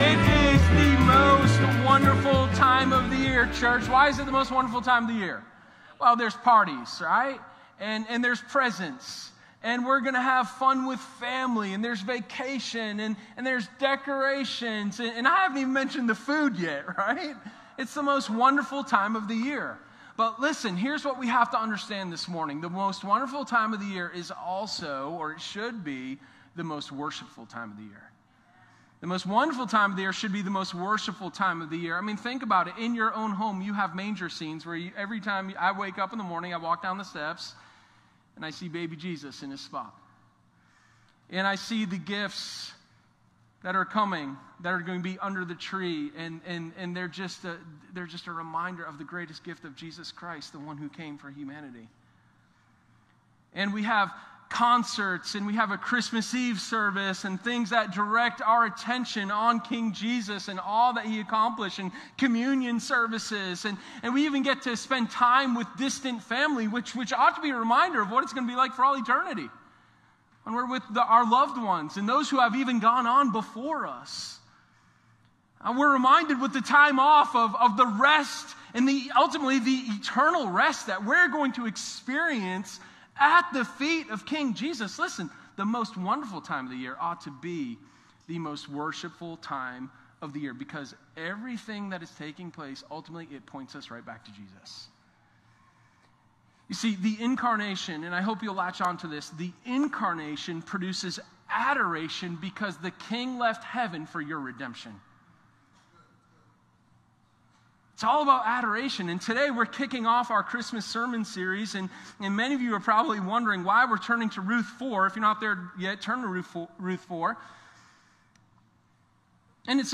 Sermon126_A-Peaceful-Redemption.m4a